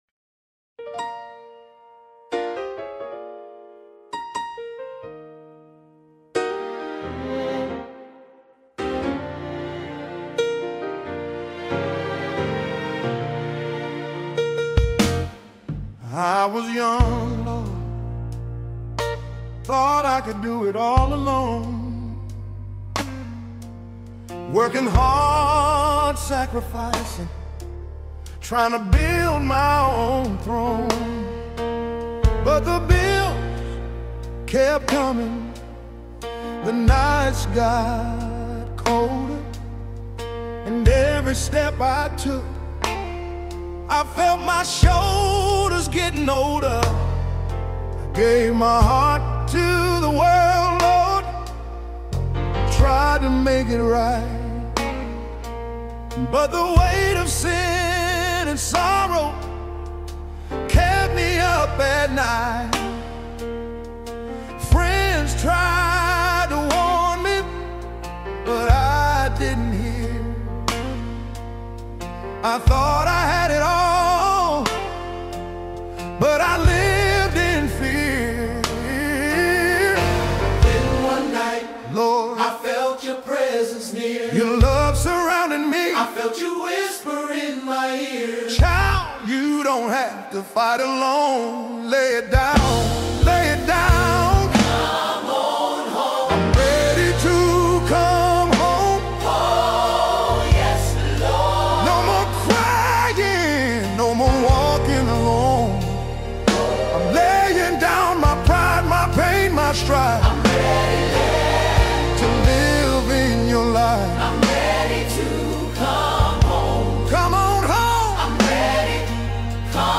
“I’m Ready To Come Home” Soulful Black Gospel R&B Testimony of Redemption & Grace